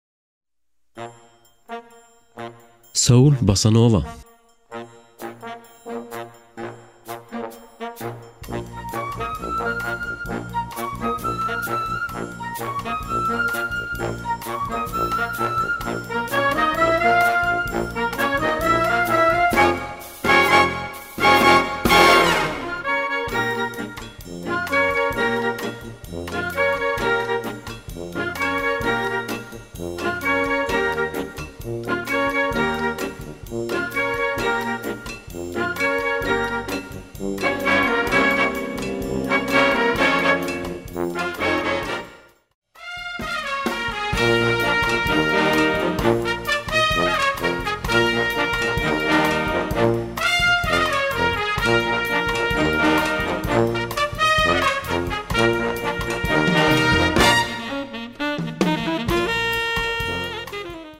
Gattung: Moderner Einzeltitel
Besetzung: Blasorchester